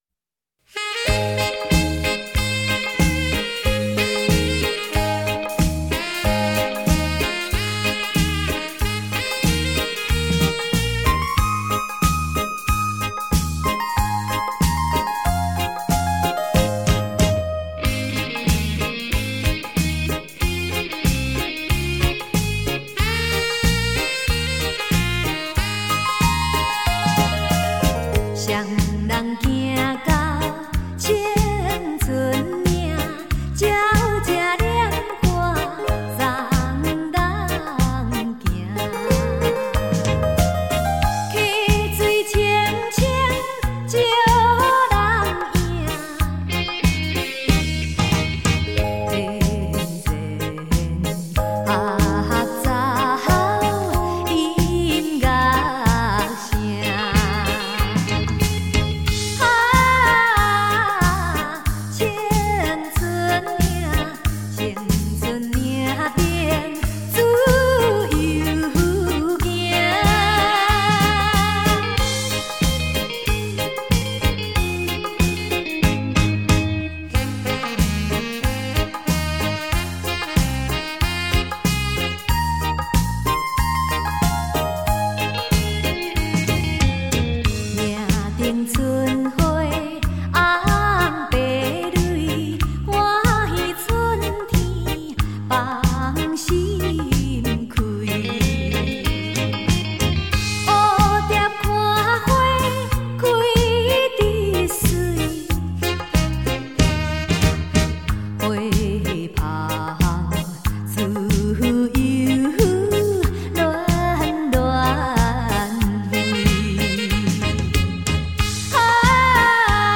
舞厅规格
吉鲁巴歌唱版
将自己投入感性的歌声中